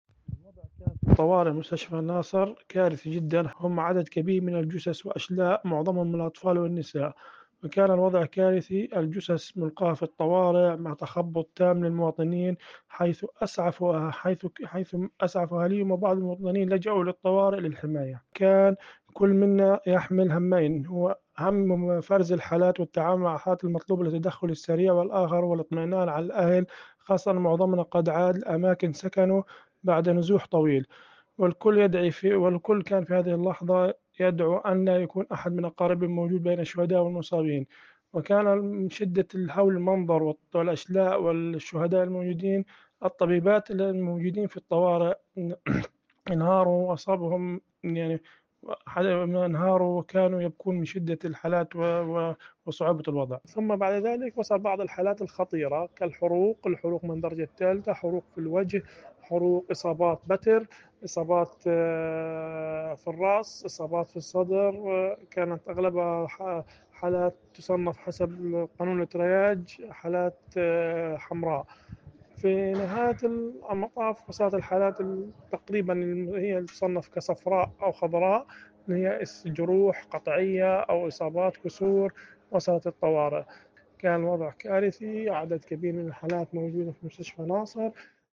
A continuación compartimos los testimonios en audio de tres de nuestros trabajadores.